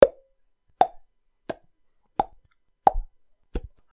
Download Mouth sound effect for free.
Mouth